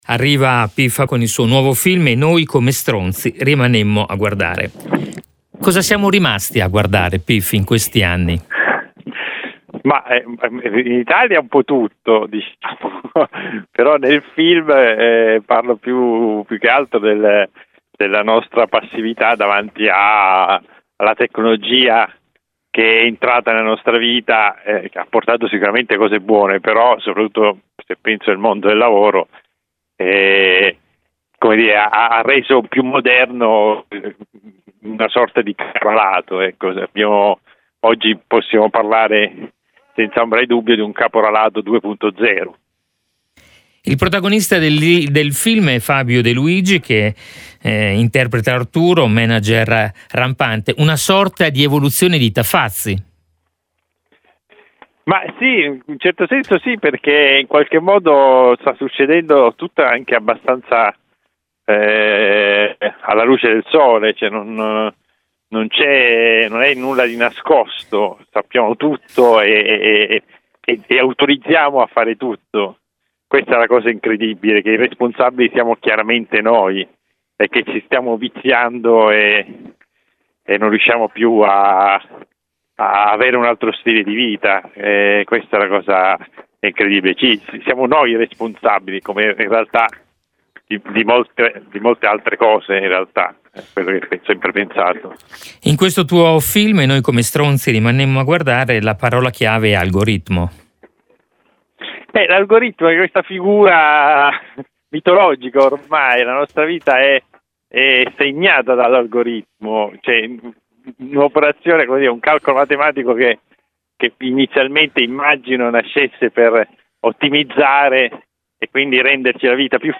Home Magazine Interviste Pif presenta il suo ultimo film “E noi come stronzi rimanemmo a...